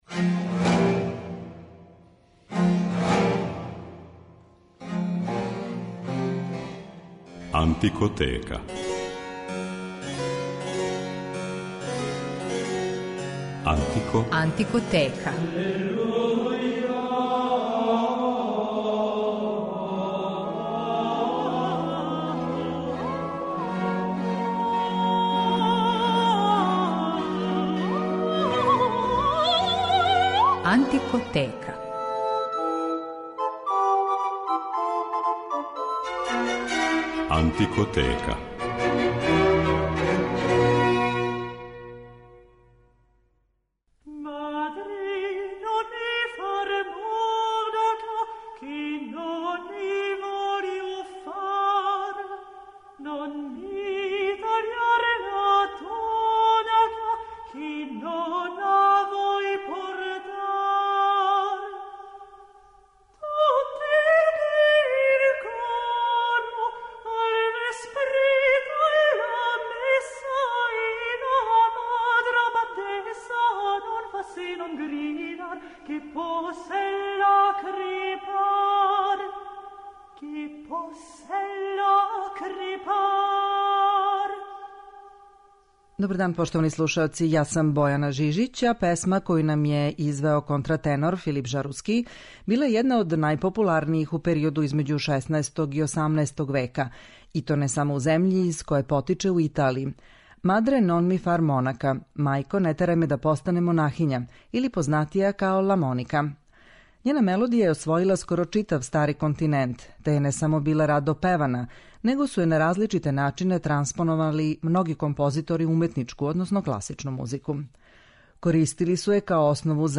Моћи ћете да чујете како су италијански, француски, немачки и енглески композитори ову чувену песму преобликовали и пребацили у различите форме и жанрове - од трио сонате и партите, до корала и мисе.